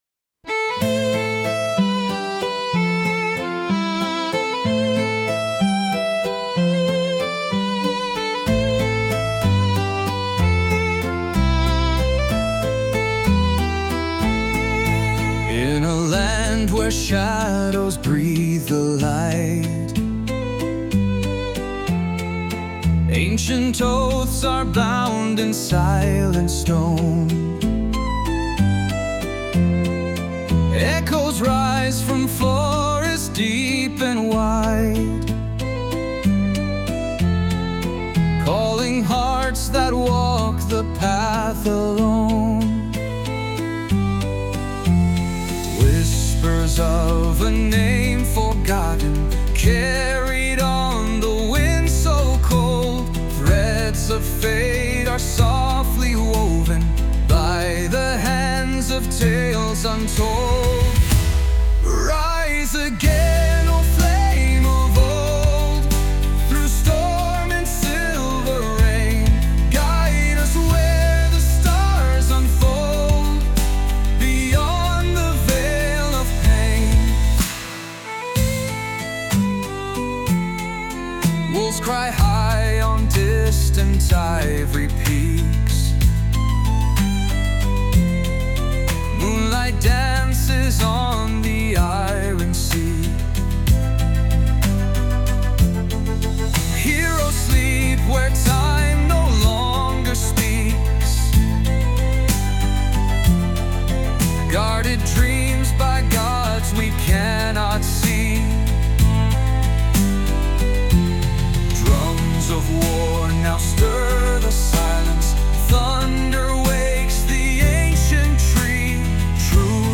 洋楽男性ボーカル著作権フリーBGM ボーカル
著作権フリーオリジナルBGMです。
男性ボーカル（洋楽・英語）曲です。
ケルトの風がそっと吹き抜けるような音色。
力強い男性ボーカルが「誓い」「記憶」「炎」といった象徴的な言葉を紡いでいきます。“